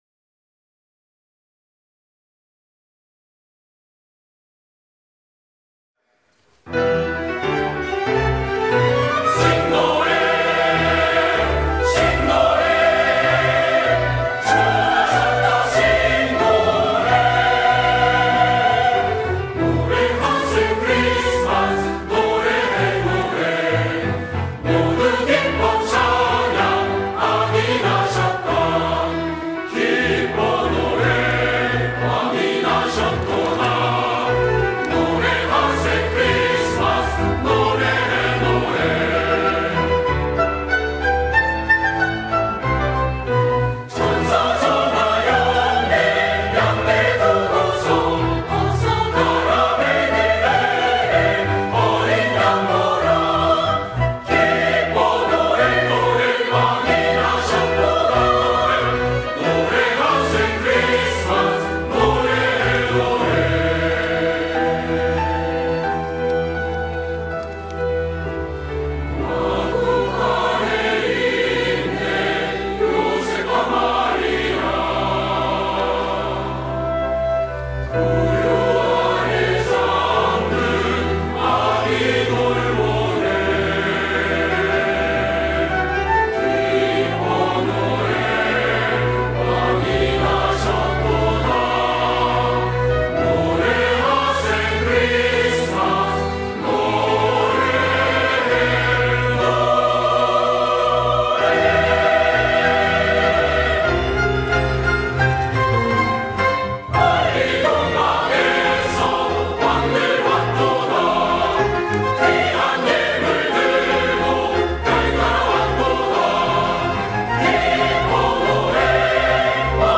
노래하세 크리스마스!(1부예배)